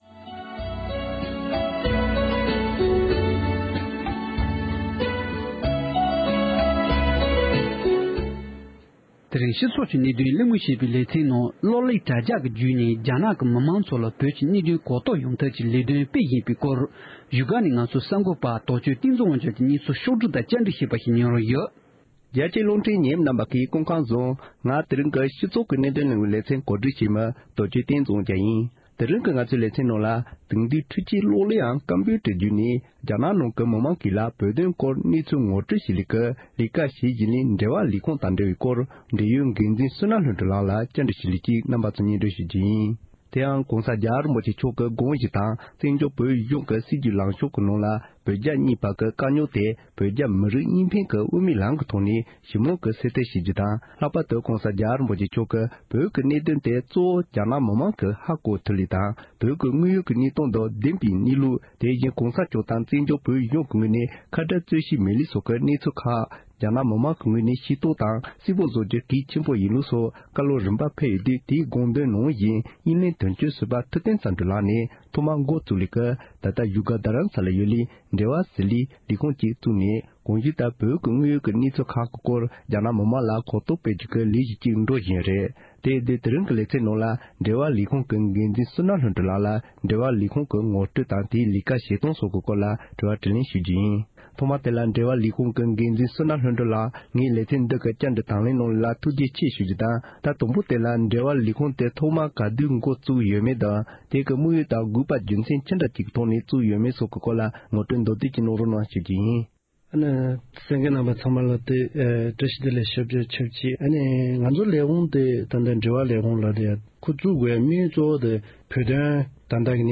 ༄༅༎དེ་རིང་གི་སྤྱི་ཚོགས་ཀྱི་གནད་དོན་གླེང་མོལ་ཞེས་པའི་ལེ་ཚན་ནང་དུ།